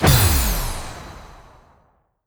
celeste_death.wav